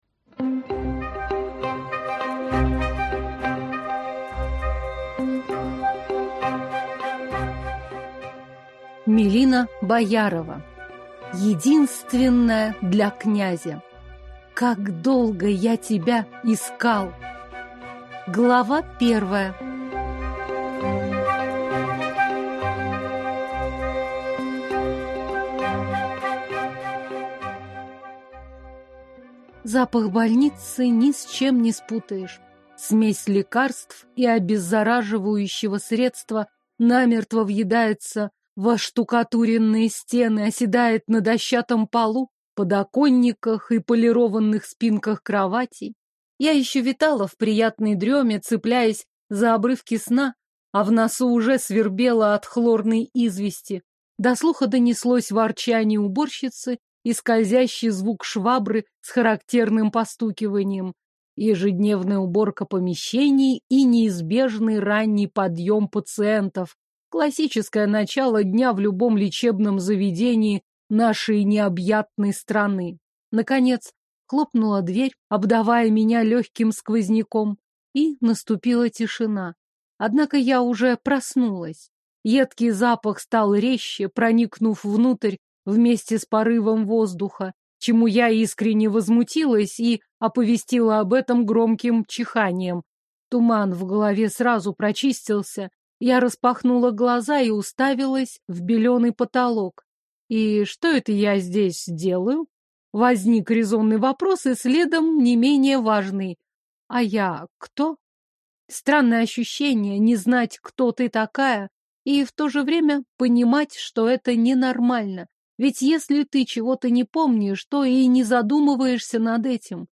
Аудиокнига Единственная для князя. Как долго я тебя искал | Библиотека аудиокниг